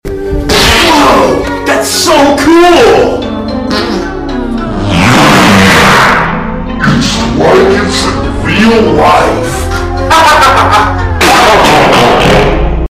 epic entbrsts farts can cause sound effects free download